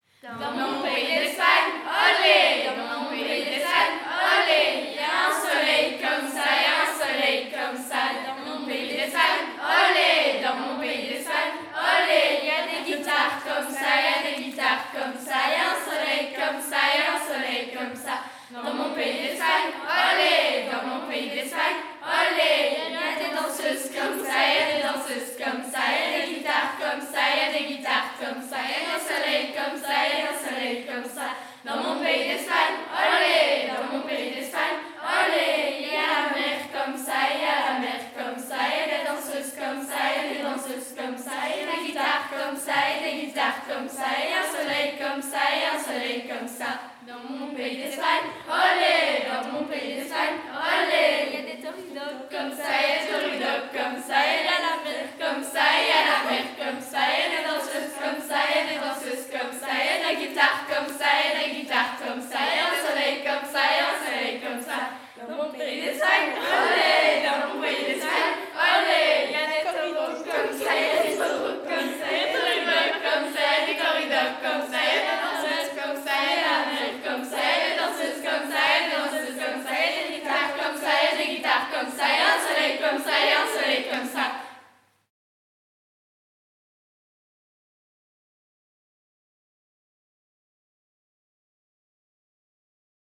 Genre : chant
Type : chant de mouvement de jeunesse
Interprète(s) : Les Guides de Bastogne
Lieu d'enregistrement : Bastogne
Chanson à récapitulation : des mots sont ajoutés au fur et à mesure des couplets.
Enregistrement réalisé dans le cadre de l'enquête Les mouvements de jeunesse en chansons.